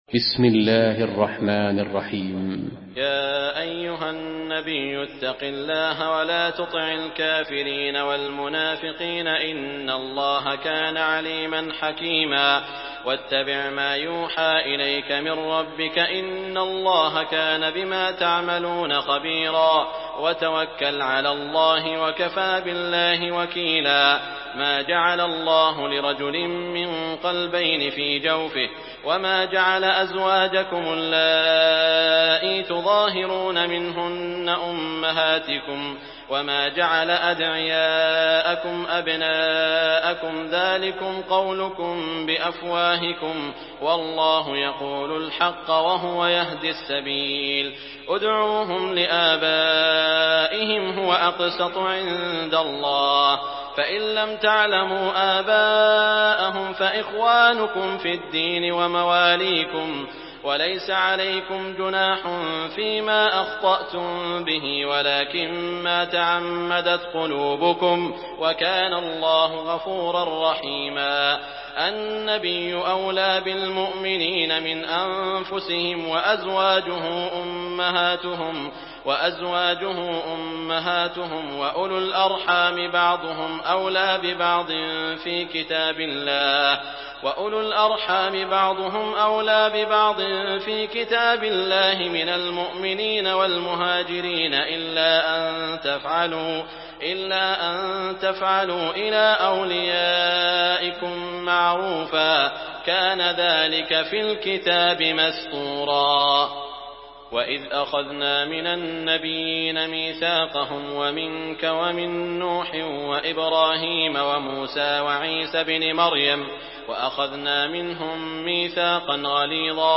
Surah Ahzab MP3 by Saud Al Shuraim in Hafs An Asim narration.
Murattal